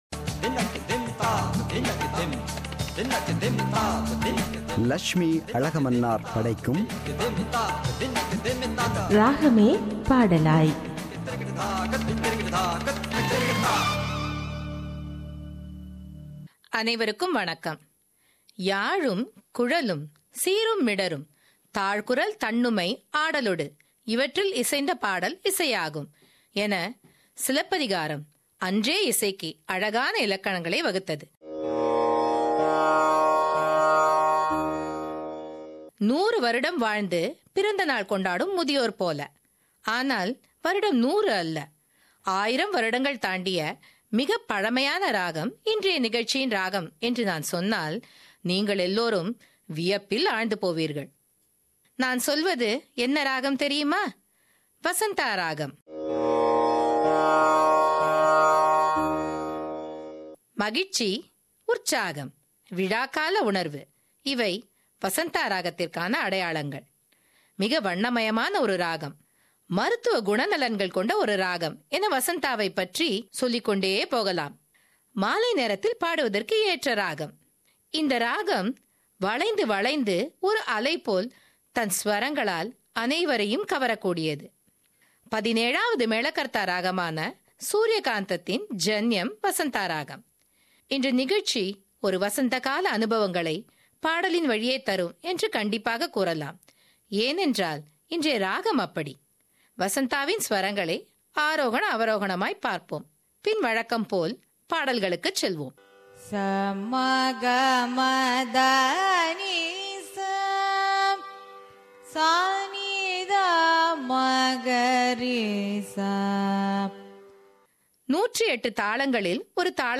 “Ragame Padalaay” – Musical Program –Part 11